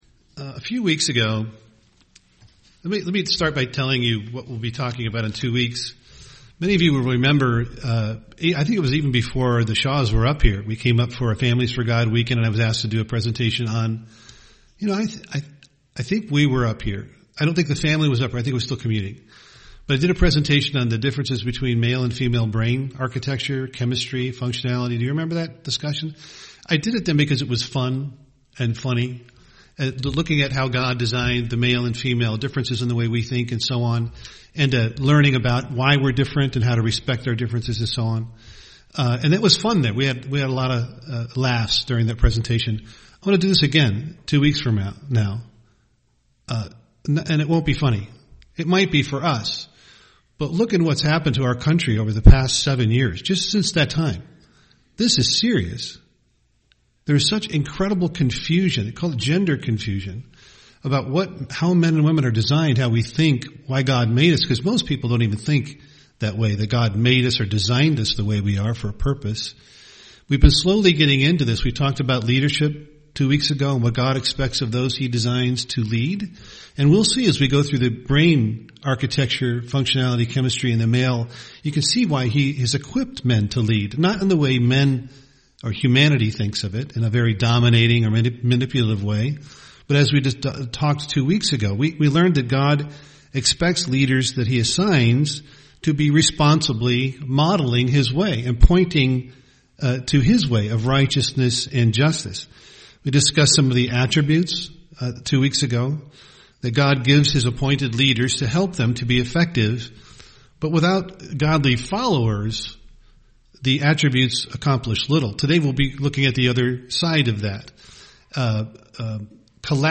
UCG Sermon submitting to God submit leadership Studying the bible?